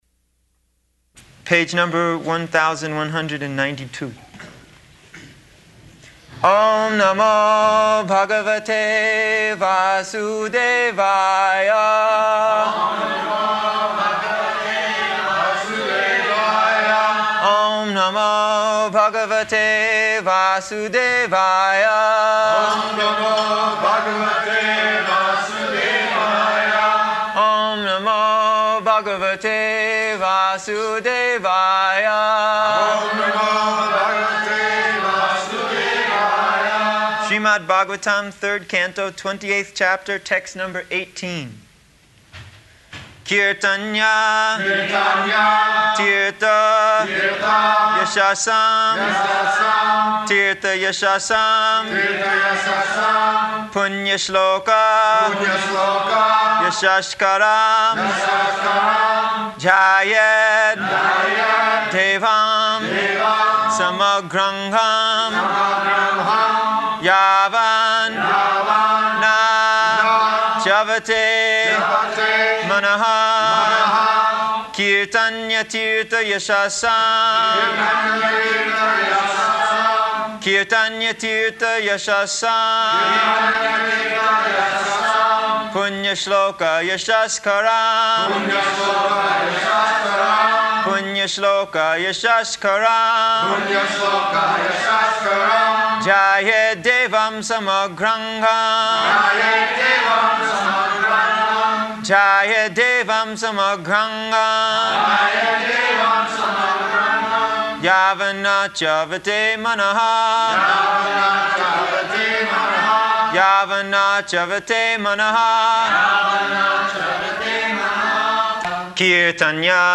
October 27th 1975 Location: Nairobi Audio file